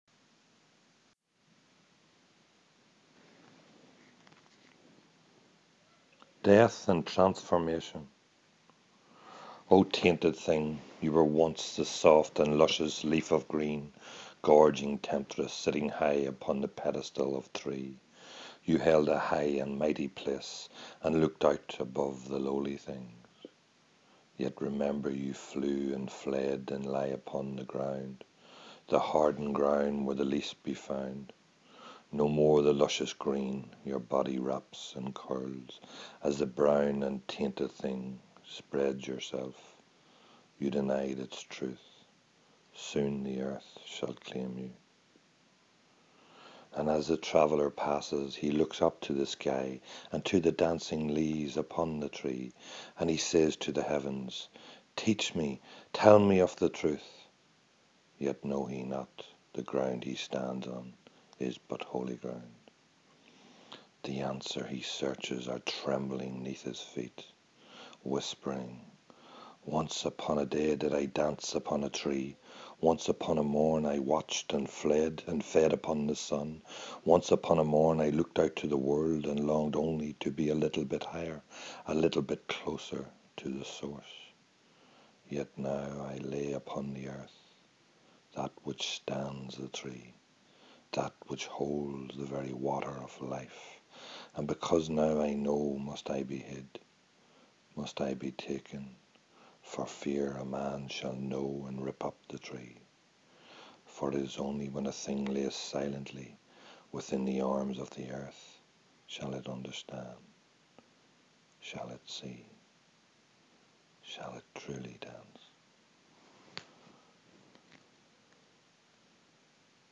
Read by the author